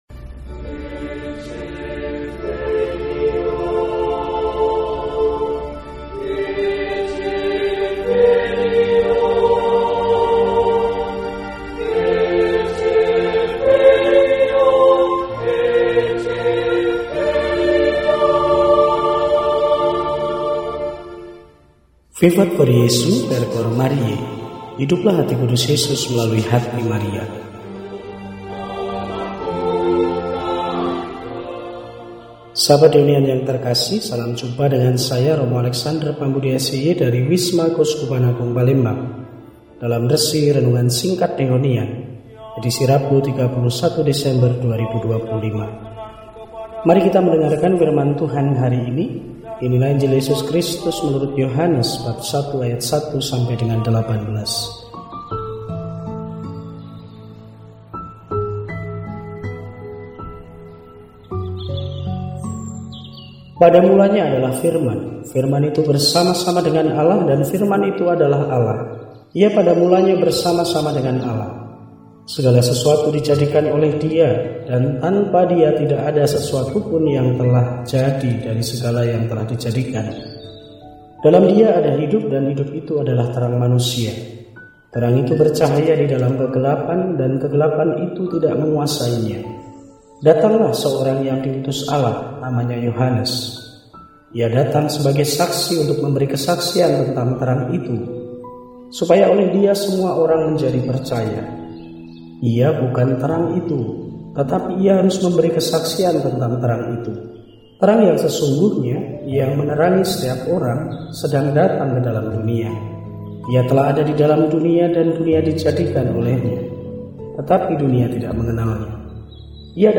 Rabu, 31 Desember 2025 – Hari Ketujuh dalam Oktaf Natal – RESI (Renungan Singkat) DEHONIAN